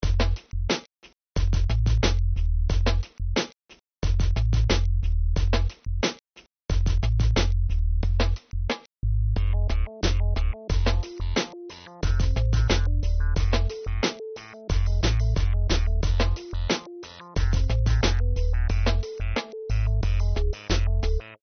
Минусовки (Hip-Hop)
Агрессивные: